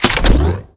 sci-fi_weapon_reload_09.wav